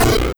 Hit7.wav